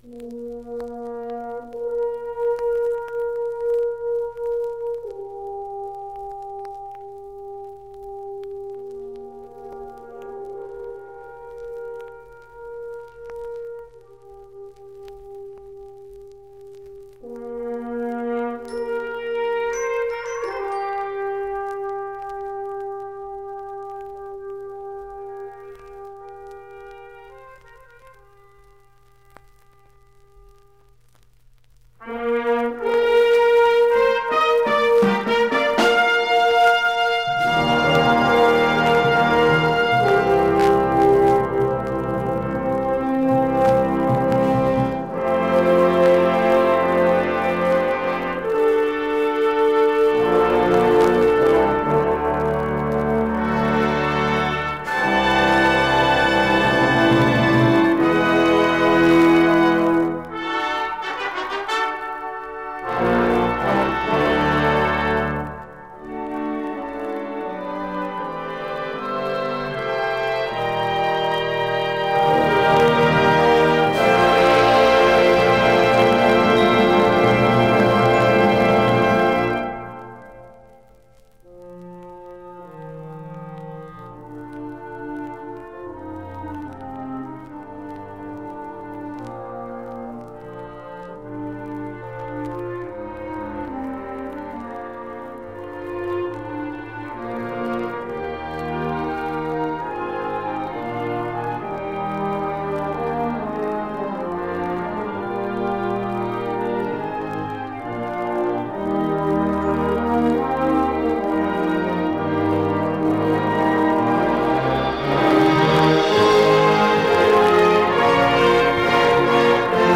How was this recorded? (Studio Recording, 1974)